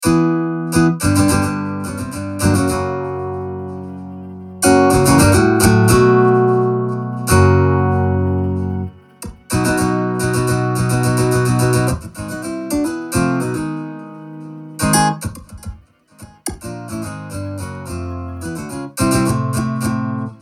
Download Free Guitar Sound Effects
Guitar